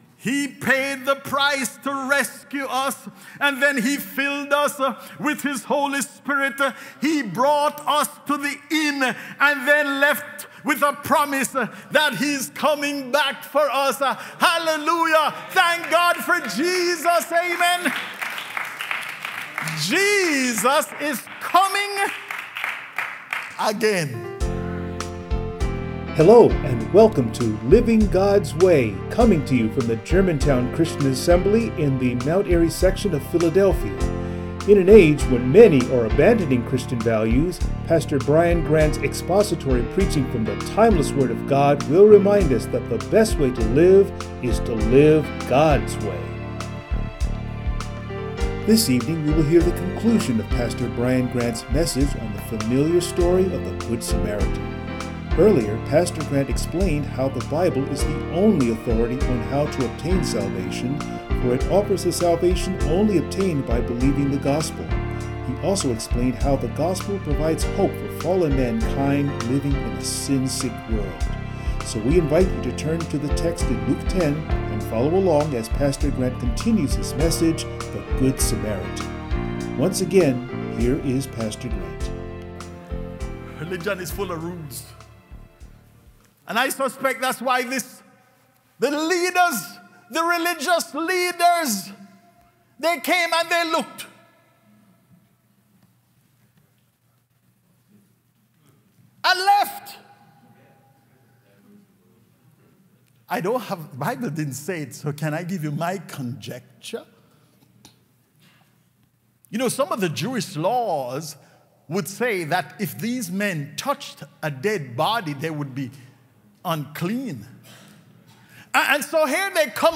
Passage: Luke 10:25-37 Service Type: Sunday Morning